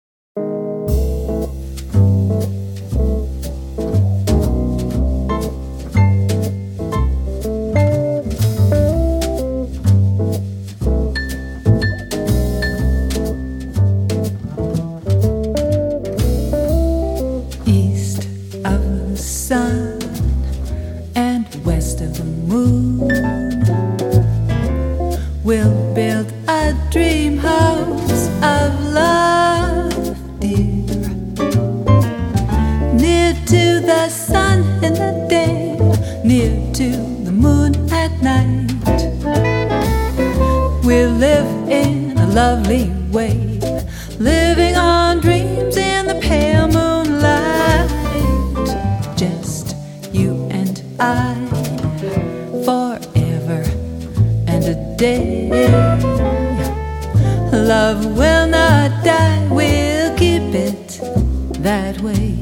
guitar, vocals